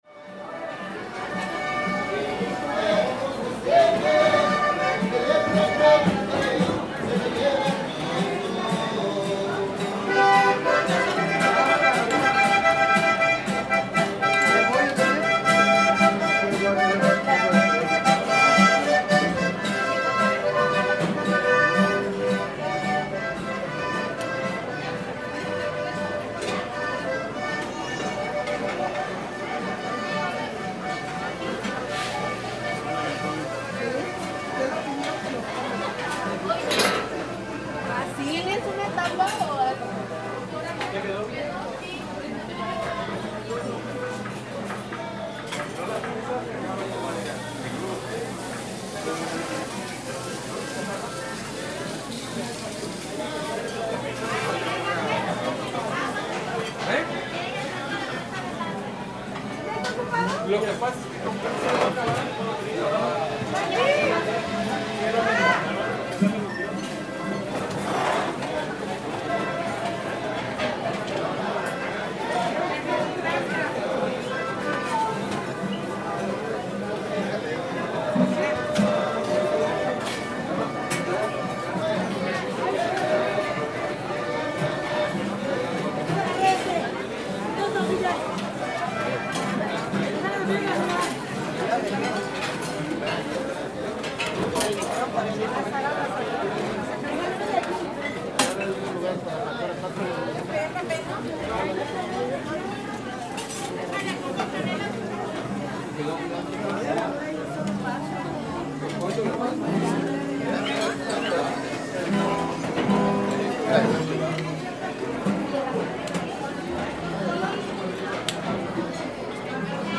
Paseo sonoro por los pasillos del mercado de comida de Santa Teresa, en Guadalajara, Jalisco. El bullicio de las cocinas a toda marcha con el run run de los comensales. Un padre e hija músicos ambientan de fondo el desayuno de muchas personas que acuden a este delicioso lugar.
Equipo: Grabadora digital Zoom, modelo H4n